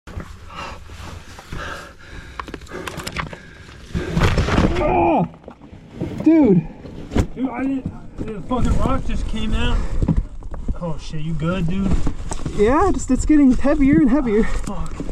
Caught In Mineshaft Collapse Sound Effects Free Download